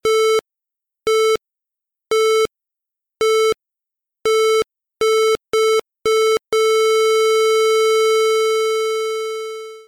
SFX心脏停止(heart stop)音效下载
SFX音效